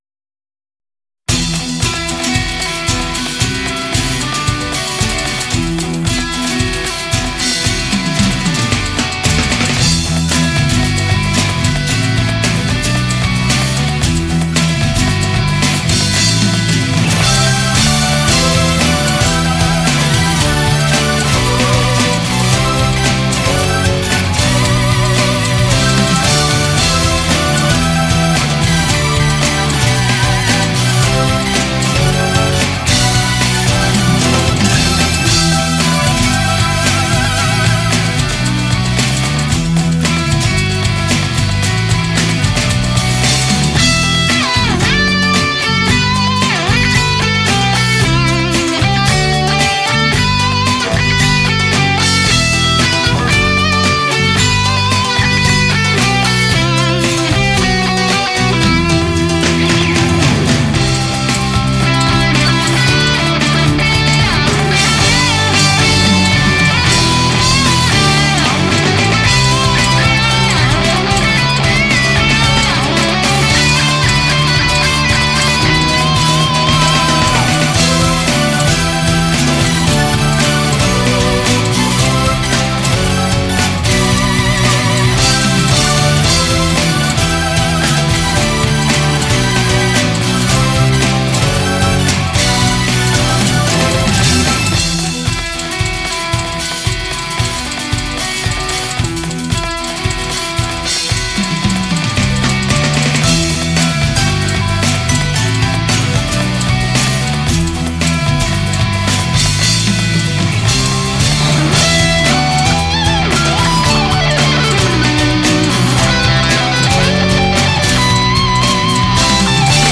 ハードロックなアレンジ。